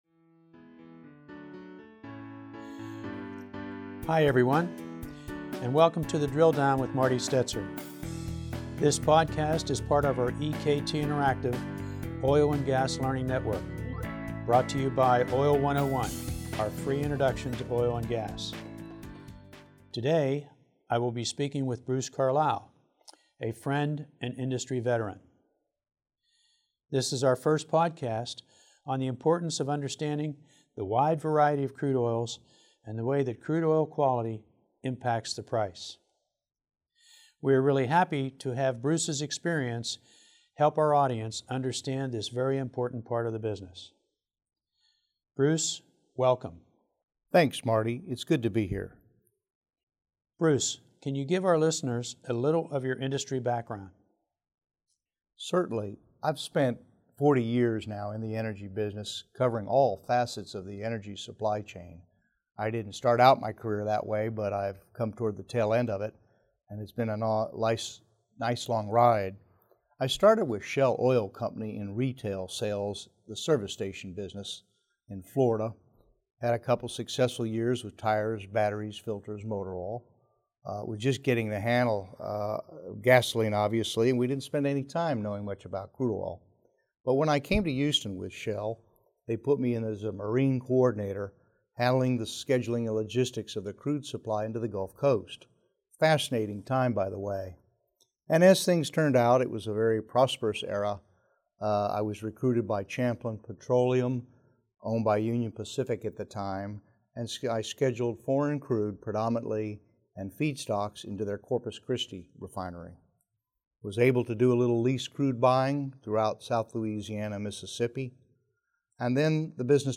Drill Down Interview